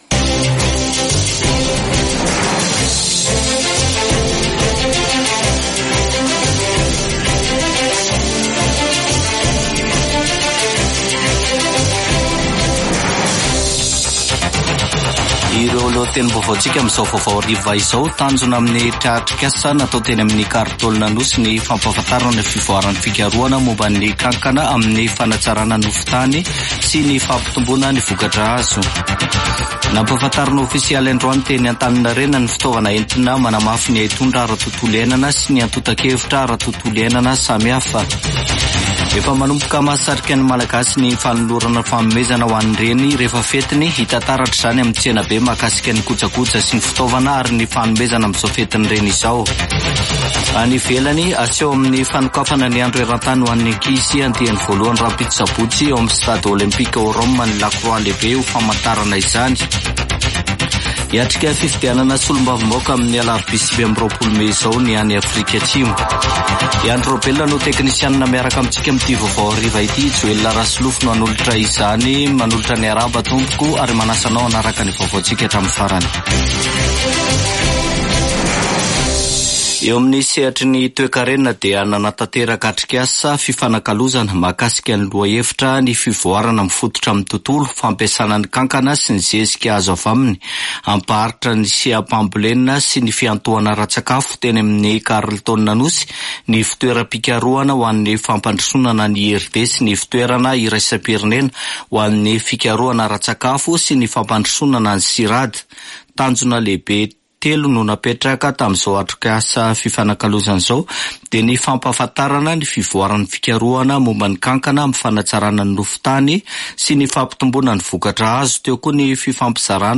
[Vaovao hariva] Zoma 24 mey 2024